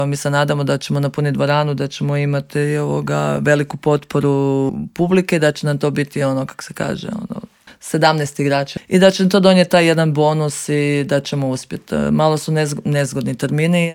O pripremama za ove dvije dvije važne utakmice sa švicarskim Amicitom iz Zuricha govorila je u Intervjuu Media servisa direktorica kluba i naša legendarna bivša rukometašica Klaudija Bubalo (Klikovac).